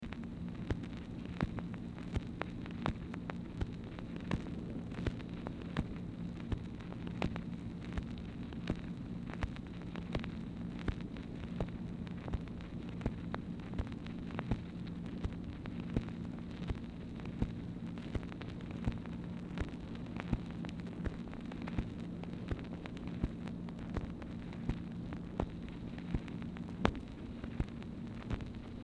Telephone conversation # 2855, sound recording, MACHINE NOISE, 4/4/1964, time unknown | Discover LBJ
Format Dictation belt